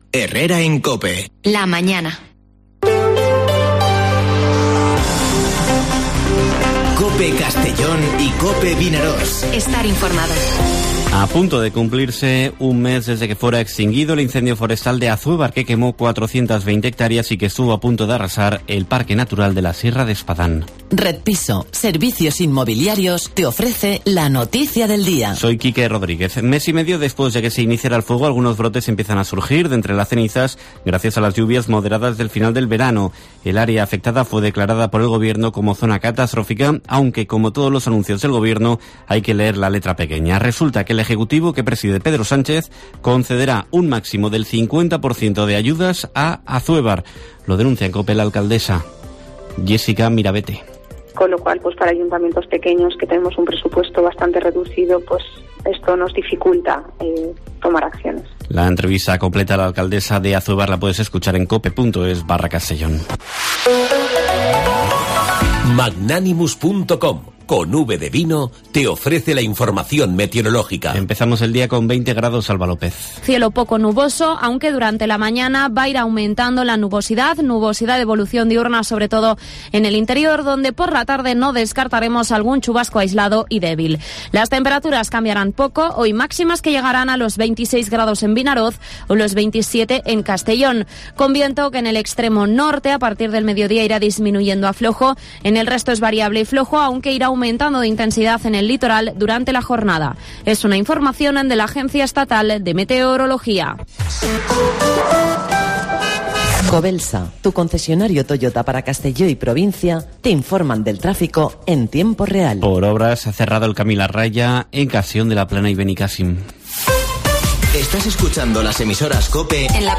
Informativo Herrera en COPE en la provincia de Castellón (29/09/2021)